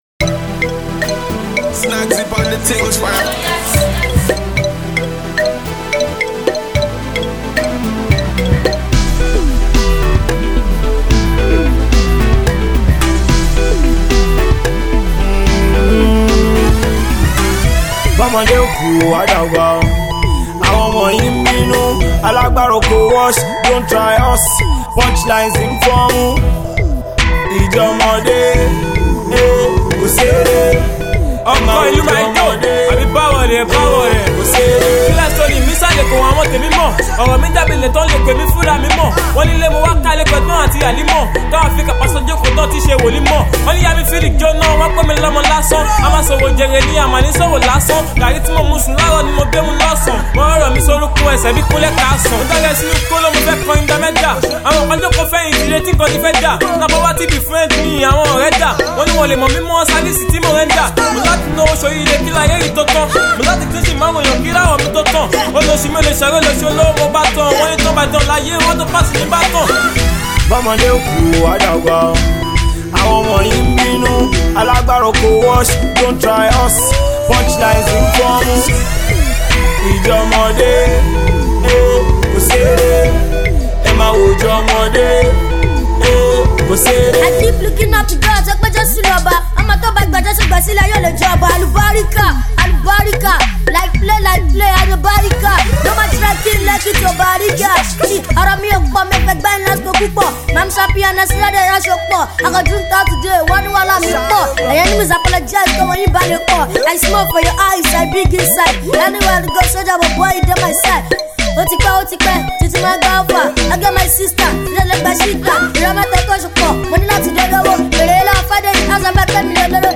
energetic battle rap
young rappers
funky  Afro beat